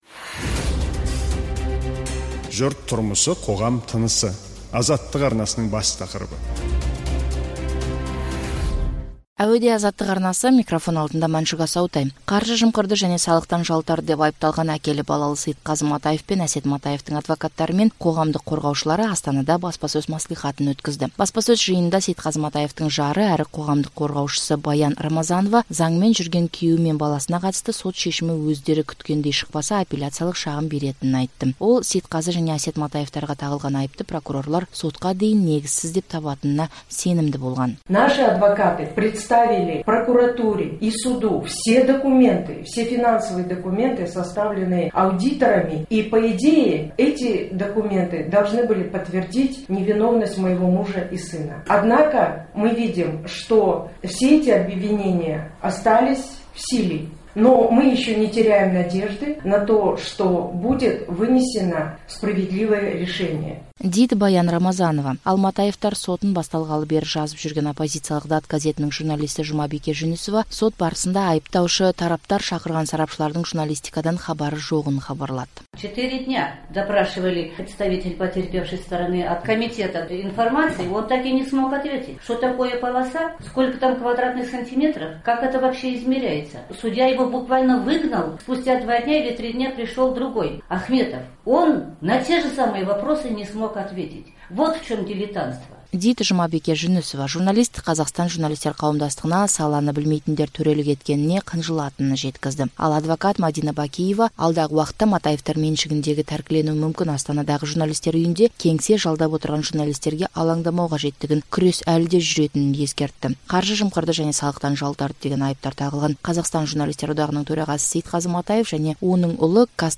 Әкелі-балалы Сейітқазы Матаев пен Әсет Матаевтың адвокаттары мен қоғамдық қорғаушылары Астанада баспасөз мәслихатын өткізді.